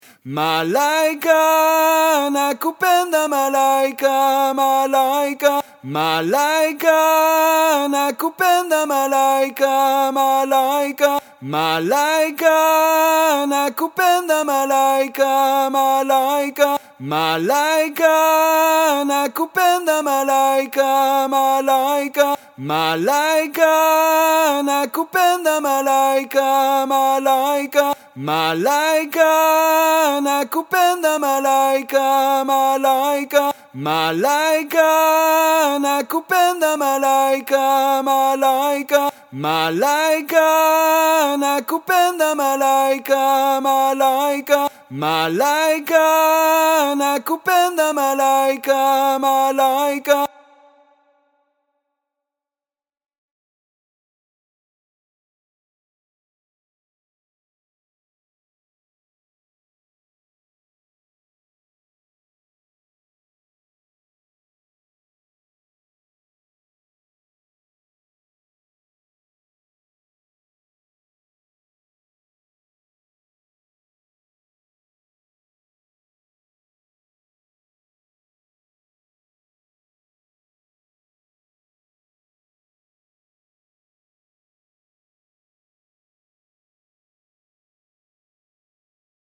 La voix 3 et son playback :